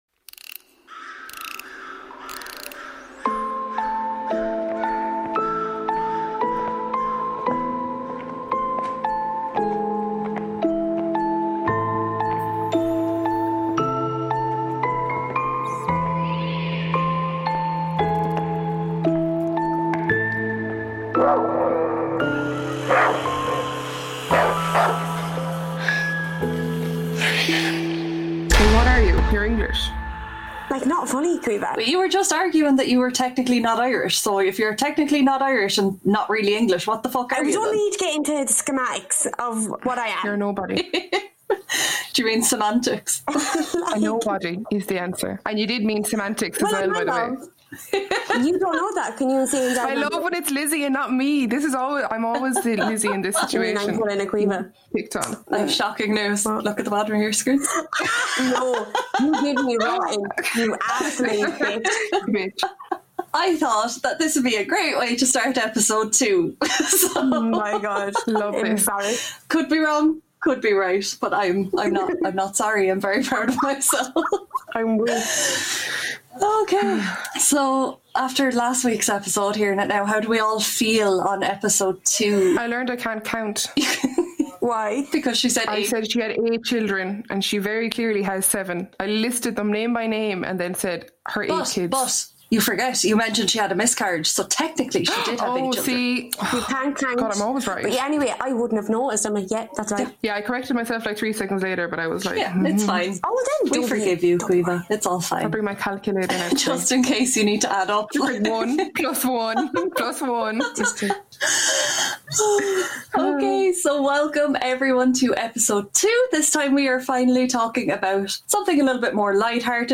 Join us as we talk about the Irish Merrow, The Japanese Kappa and the African beauty Mami Wata. We had some slight technical difficulties so we ask you to forgive our audio quality drops in this episode.